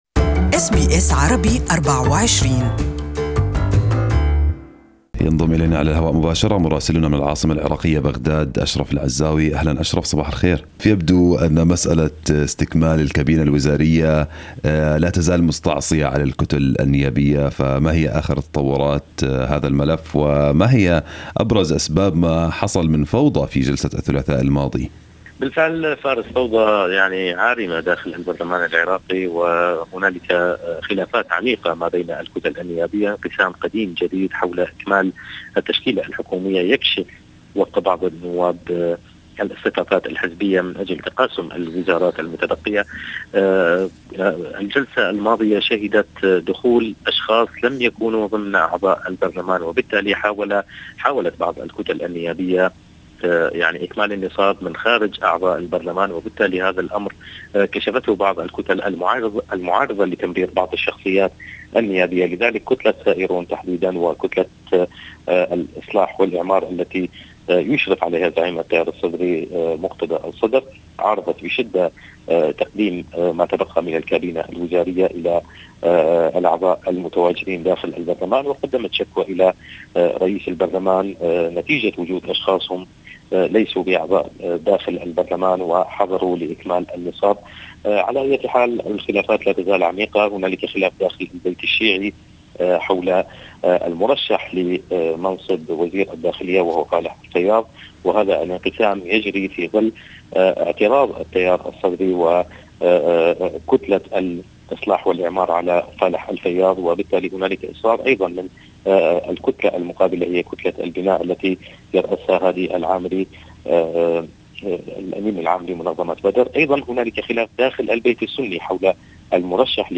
Listen to the full report from Baghdad in Arabic above